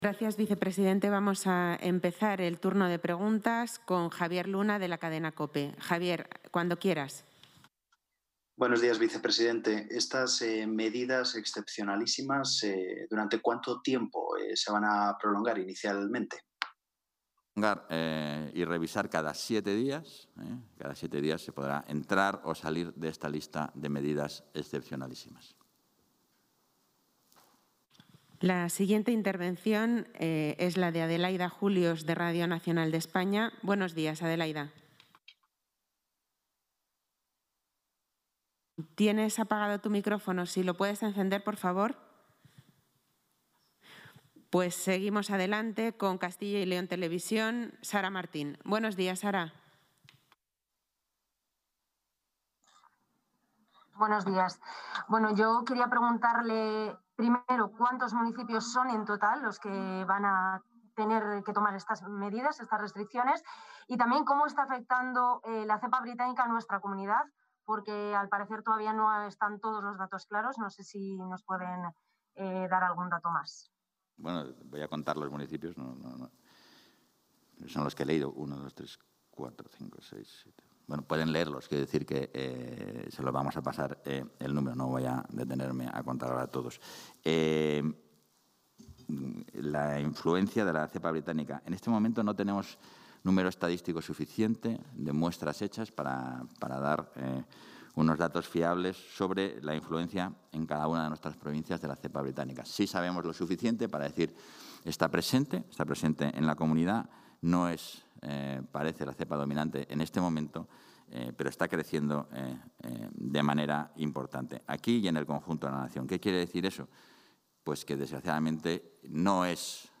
Intervención del vicepresidente y portavoz.